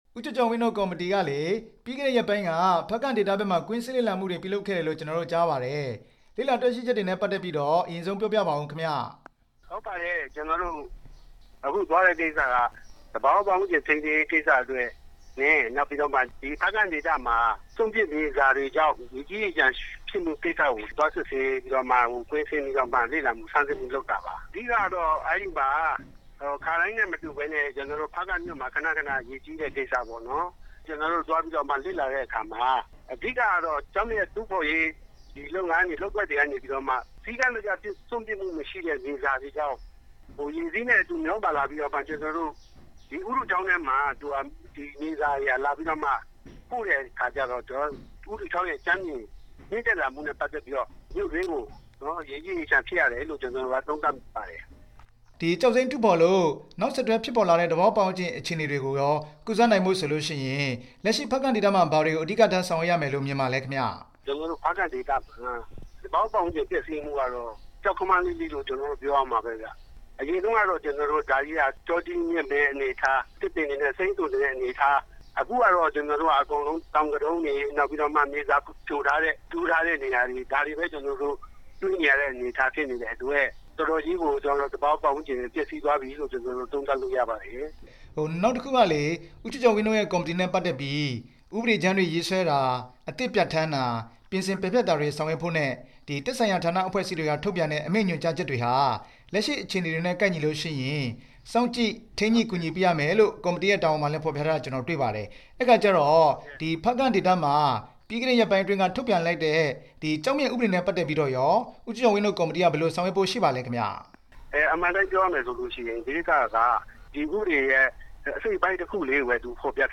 မိုးညှင်းမြို့နယ် လွှတ်တော်ကိုယ်စားလှယ် ဦးကျော်ကျော်ဝင်းနဲ့ မေးမြန်းချက်